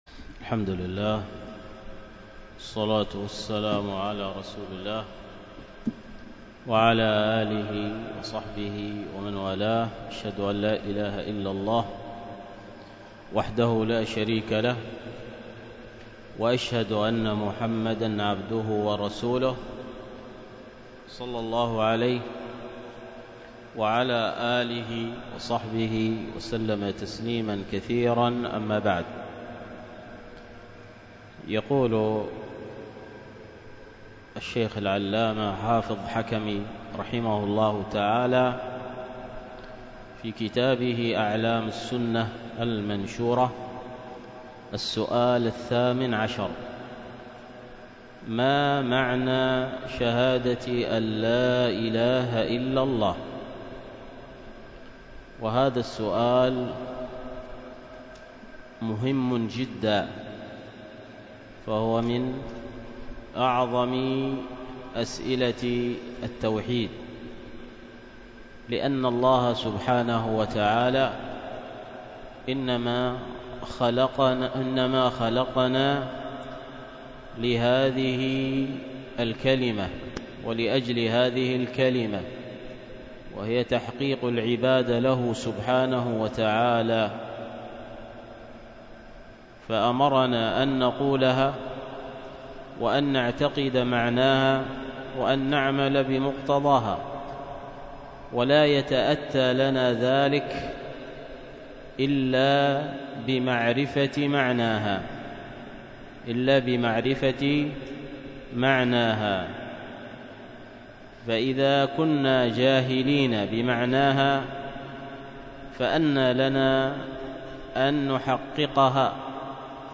الدرس في أعلام السنة المنشورة لاعتقاد الطائفة الناجية المنصورة 12